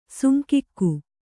♪ sunkikku